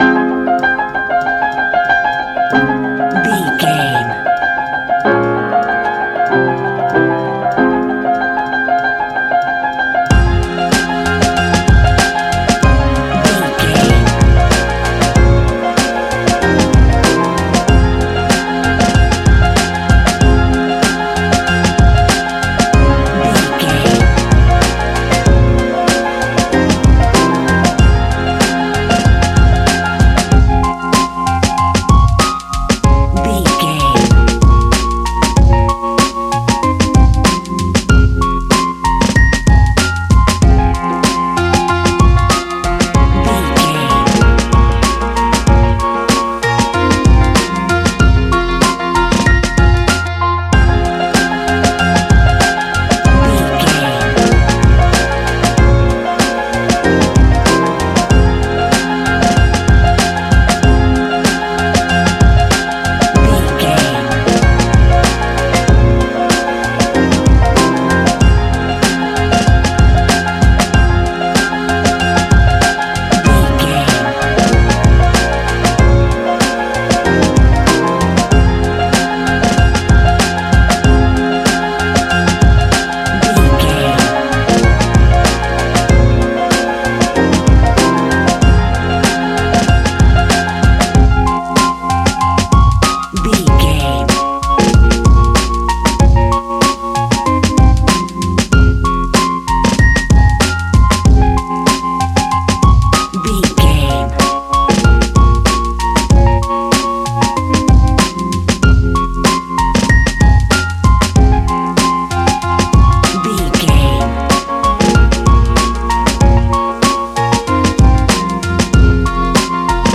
Ionian/Major
A♯
Lounge
sparse
new age
chilled electronica
ambient
atmospheric